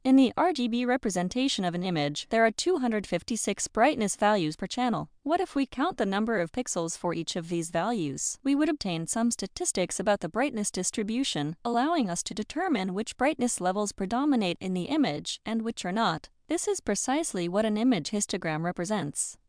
Для общего использования мне понравился vits-piper-en_US-hfc_female-medium: